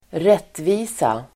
Uttal: [²r'et:vi:sa]